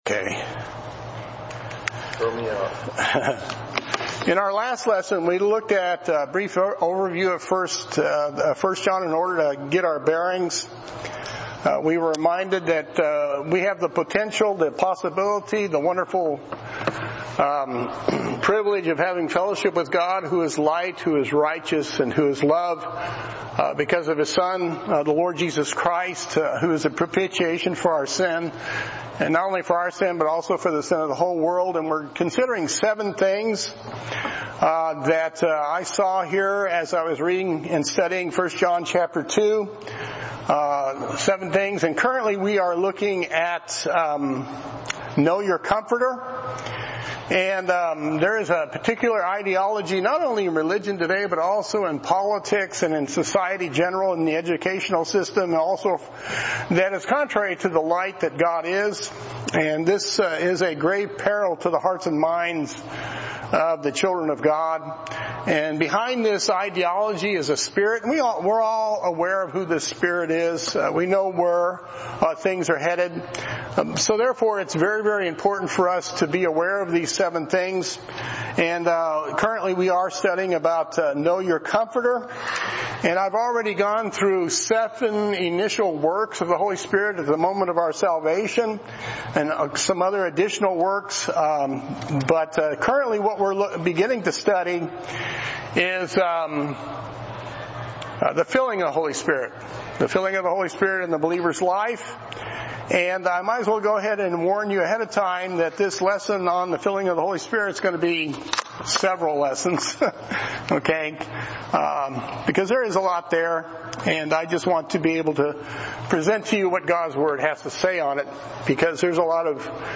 A Study in 1 John Current Sermon